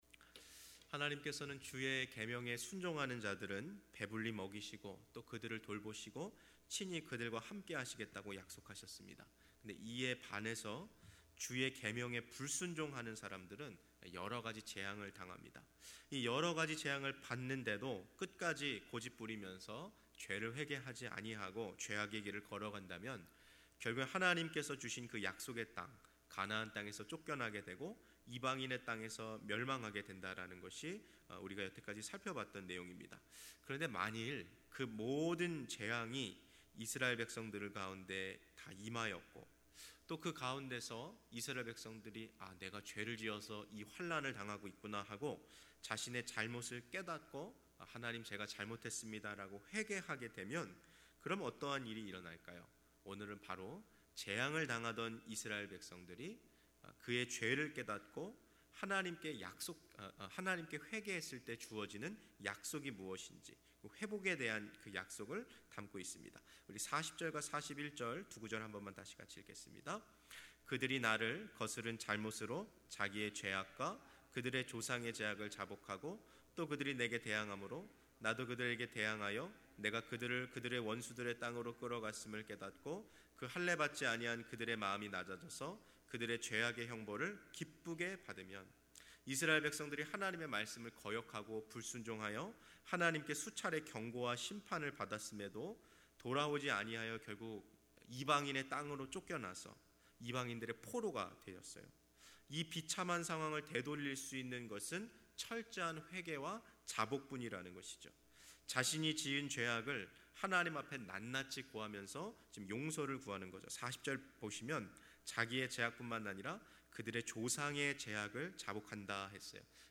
2. 새벽예배설교